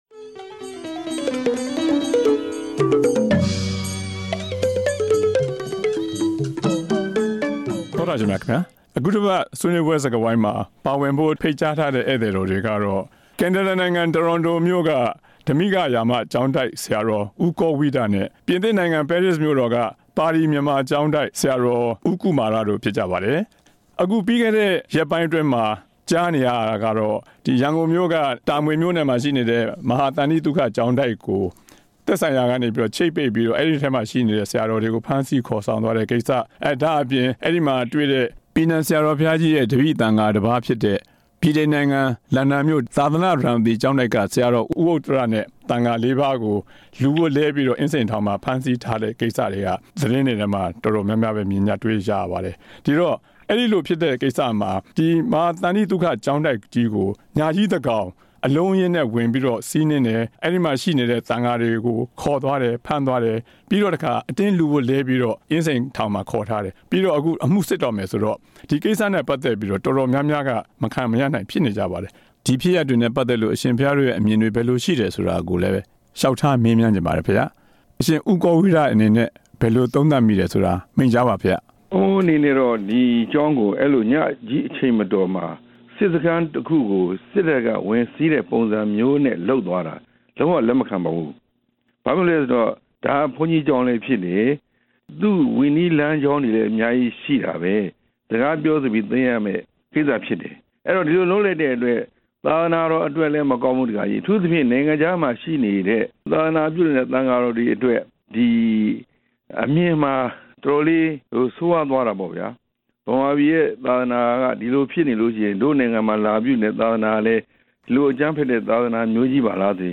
မဟာသန္တိသုခကျောင်းတိုက်ကိစ္စ ဆရာတော် နှစ်ပါးနဲ့ ဆွေးနွေးချက်